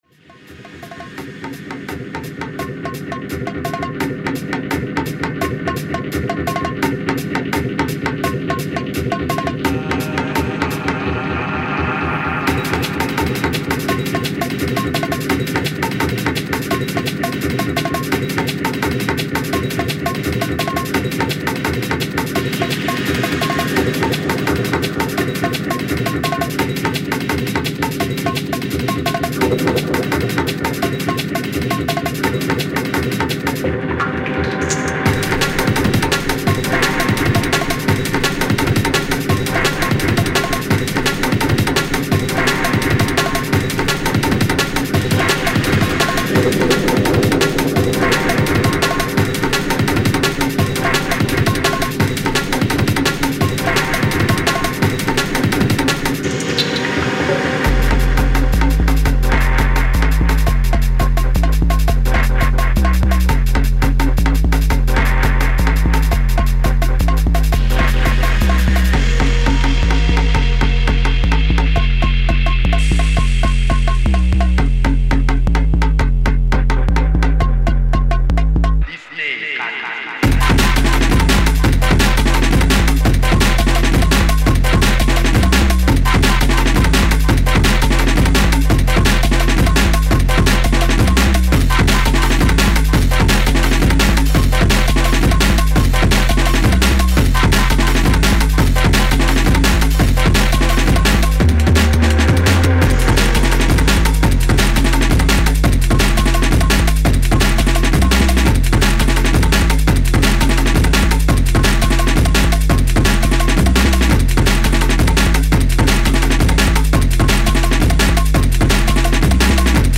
futuristic dark sounds and the early Techstep scene in DNB